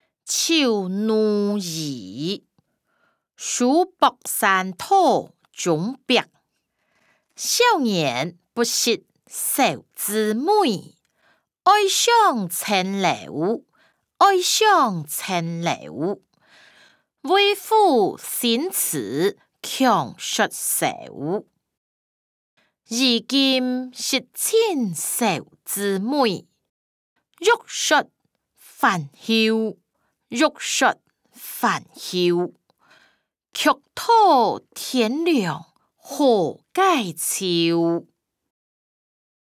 詞、曲-醜奴兒•書博山道中壁音檔(大埔腔)